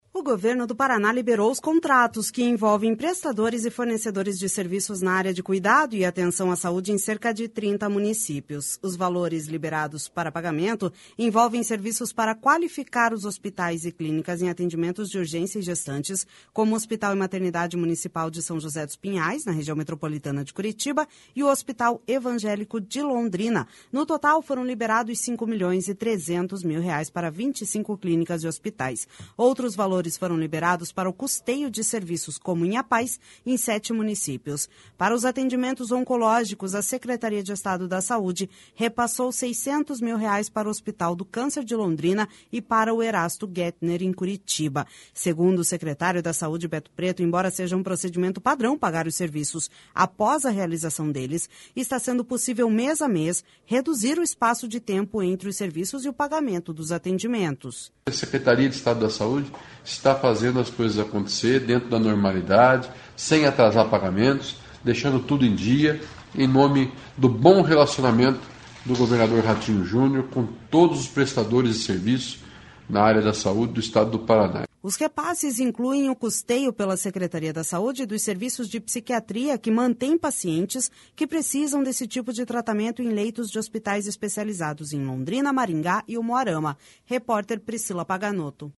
Segundo o secretário da Saúde, Beto Preto, embora seja um procedimento padrão pagar os serviços após a realização deles, está sendo possível, mês a mês, reduzir o espaço de tempo entre os serviços e o pagamento dos atendimentos.// SONORA BETO PRETO//Os repasses incluem o custeio, pela Secretaria da Saúde, dos serviços de psiquiatria que mantém pacientes que precisam desse tipo de tratamento em leitos de hospitais especializados em Londrina, Maringá e Umuarama.